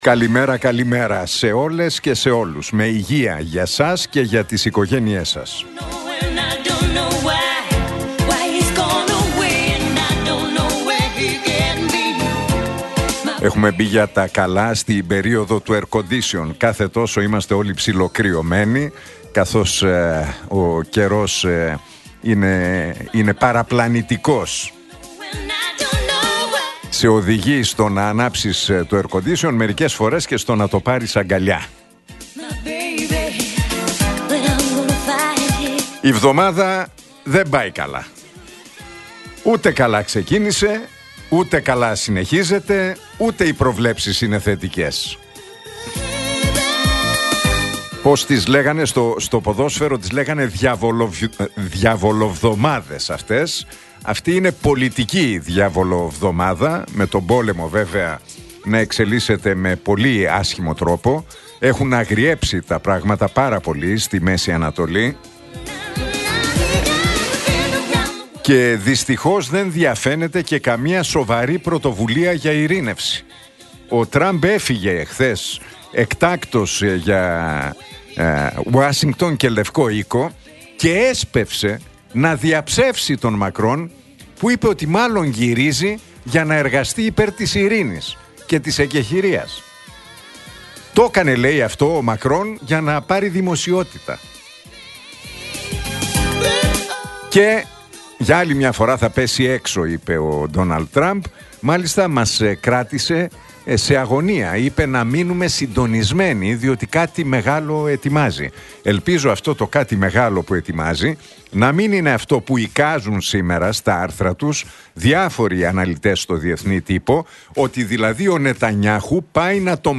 Ακούστε το σχόλιο του Νίκου Χατζηνικολάου στον ραδιοφωνικό σταθμό Realfm 97,8, την Τρίτη 17 Ιουνίου 2025.